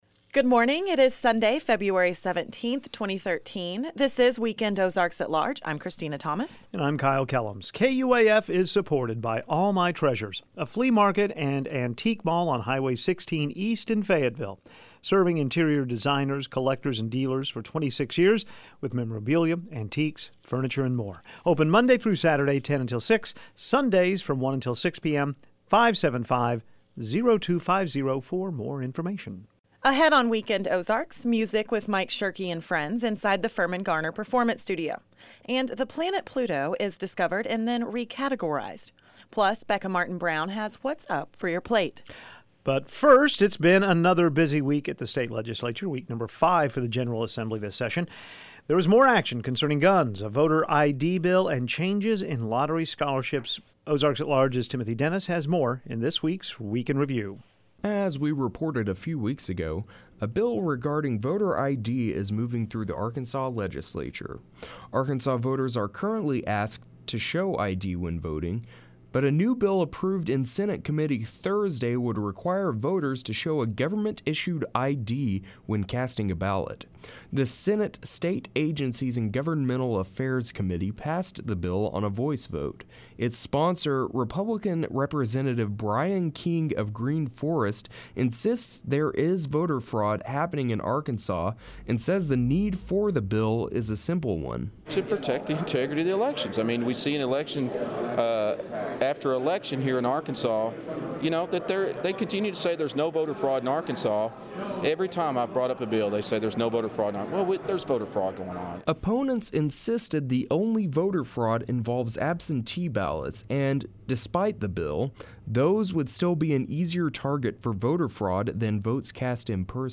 inside the Firmin-Garner Performance Studio